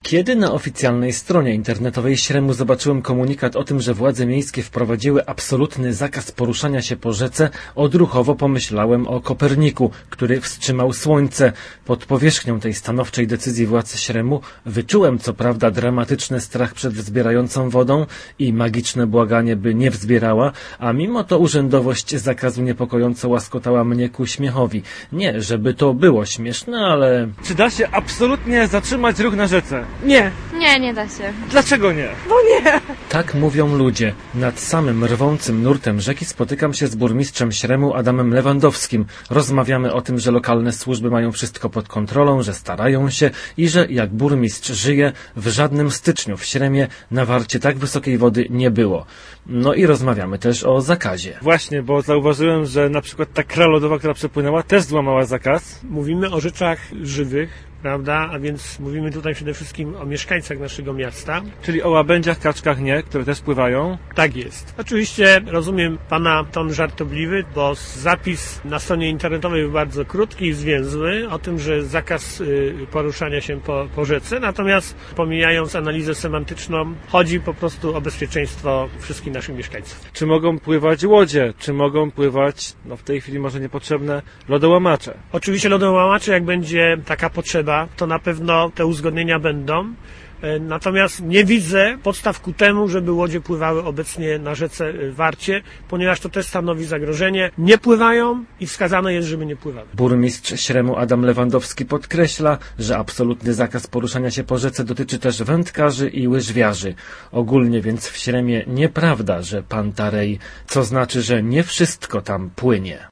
Tak jak zrobił nasz reporter, który był nad rzeką Wartą w Śremie.